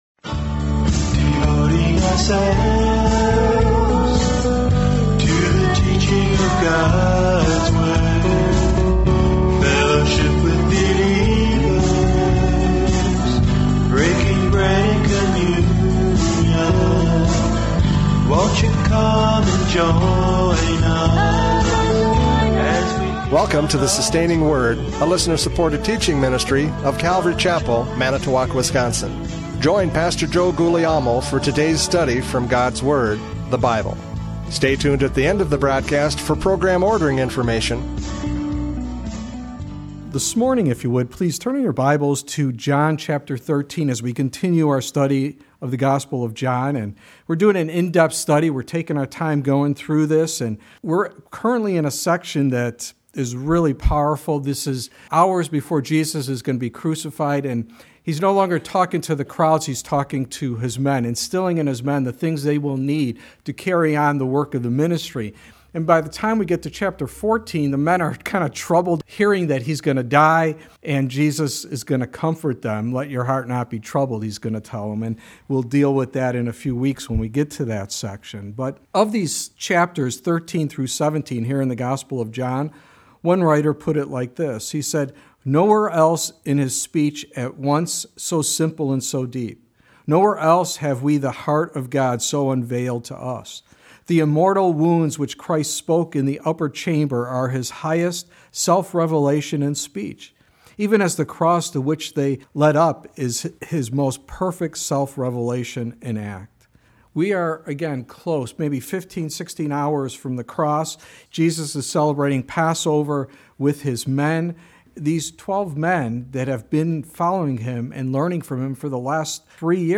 John 13:21-30 Service Type: Radio Programs « John 13:1-20 An Example of Humility!